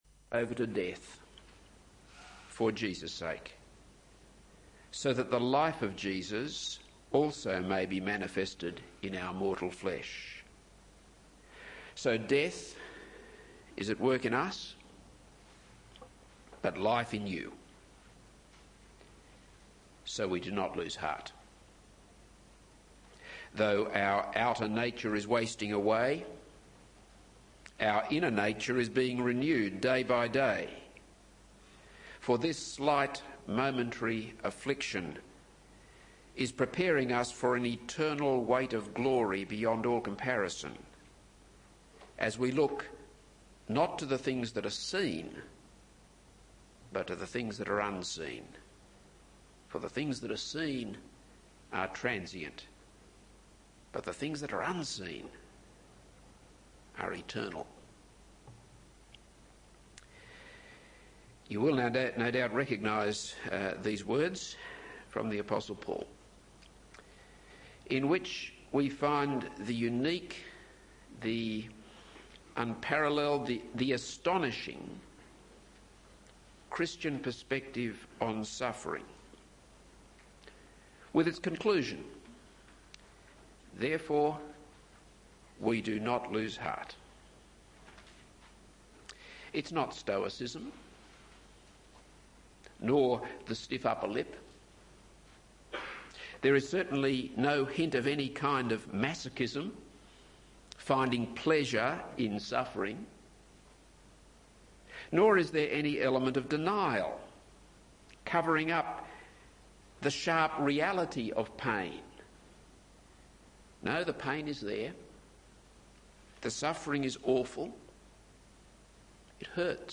This is a sermon on 1 Samuel 23:15-29.